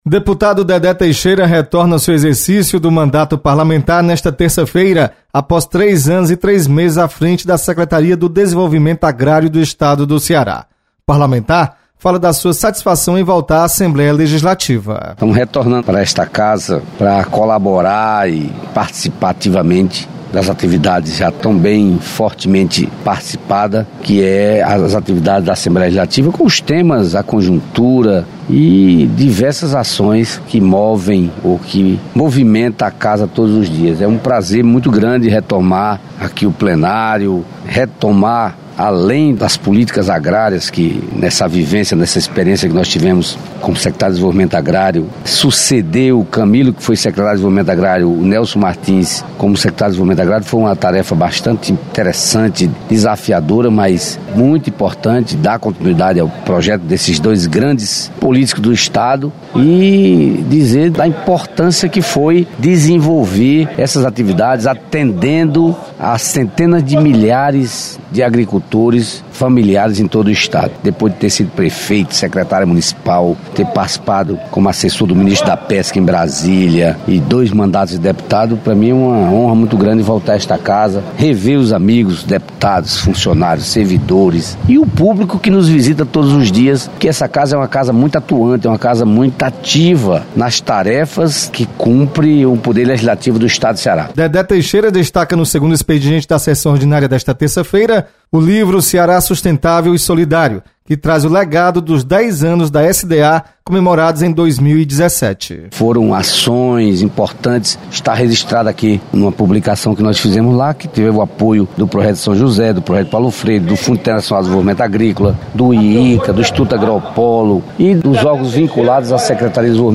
Ex-secretário retorna ao Parlamento. Repórter